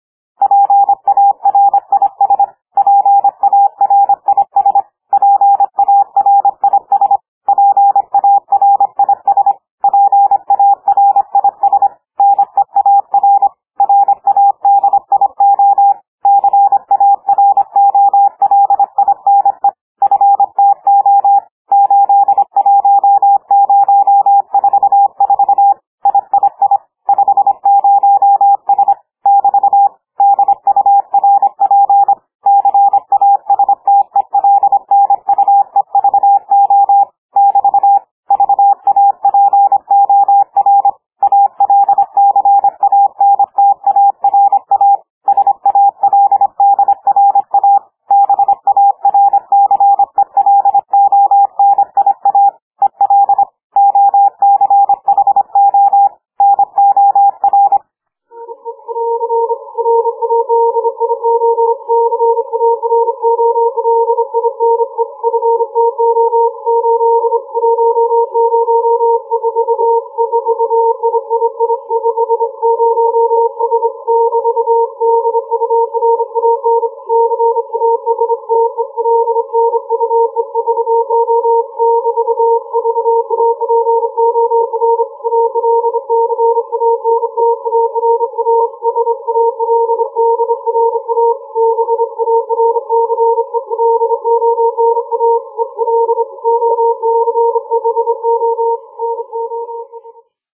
[CW] Fast hand speed - over 25 wpm
Edison cylenders from the early 20th century - one had "fast radio code".
and calculations, the code speed seems to be just about 27 wpm.
fast_hand_key.mp3